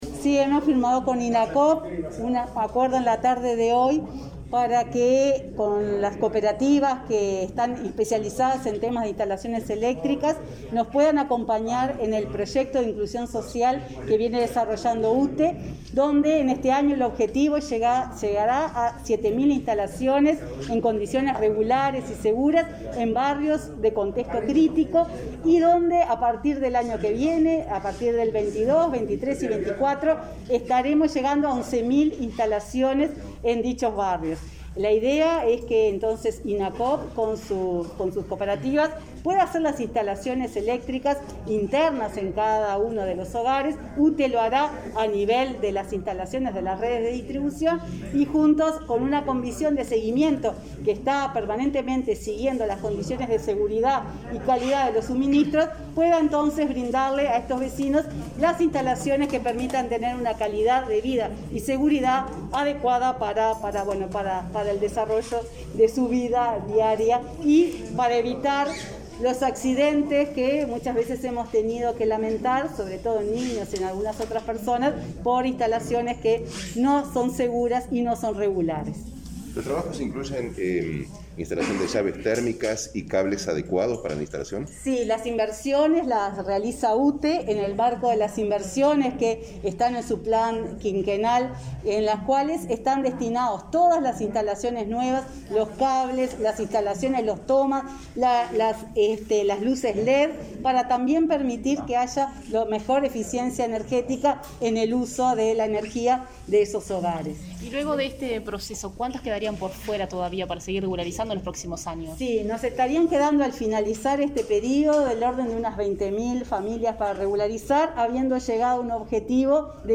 Declaraciones a la prensa de la presidenta de UTE, Silvia Emaldi
Emaldi-prensa.mp3